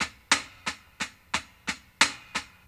Closed Hats
ILLMD016_HH_FIRSTTIME.wav